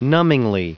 Prononciation du mot : numbingly
numbingly.wav